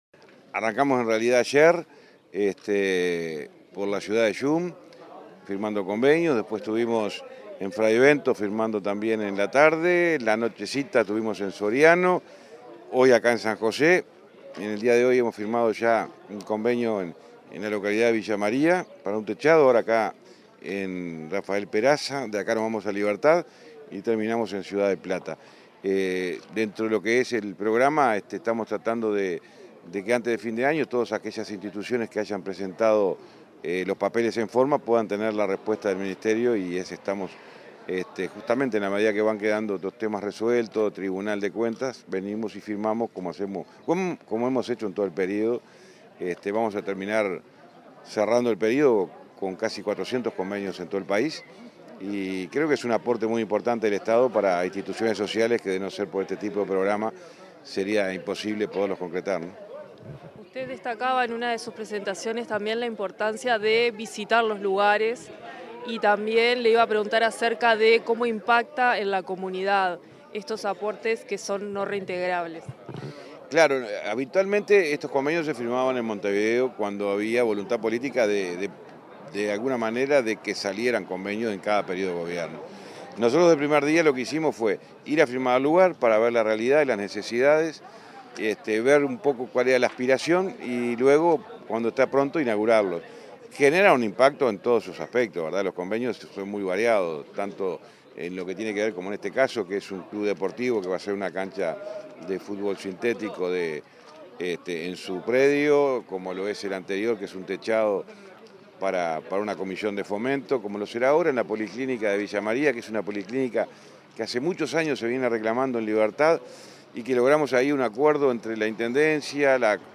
Declaraciones del ministro de Transporte y Obras Públicas, José Luis Falero
Declaraciones del ministro de Transporte y Obras Públicas, José Luis Falero 13/11/2024 Compartir Facebook X Copiar enlace WhatsApp LinkedIn Tras la firma de preacuerdos de convenios sociales con instituciones en el departamento de San José, se expresó el ministro de Transporte y Obras Públicas, José Luis Falero.